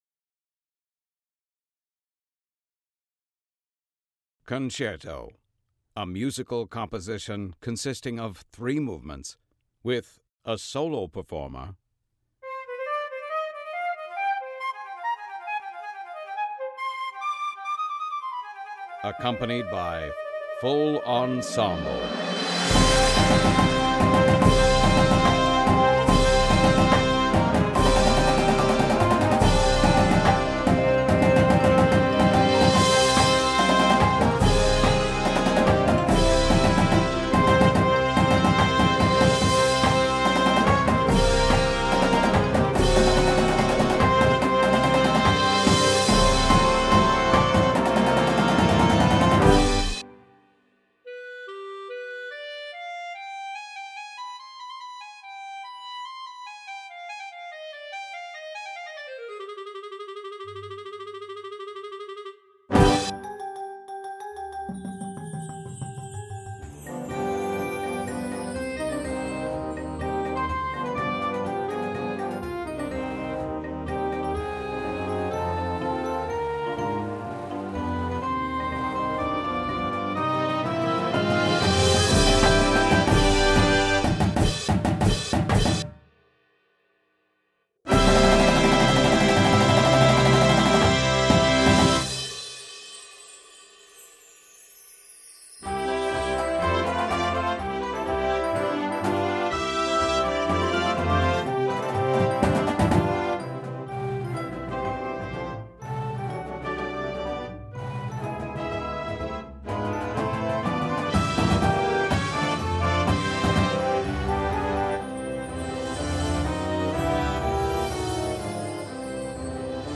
Instrumentation:
• Tuba
• Snare Drum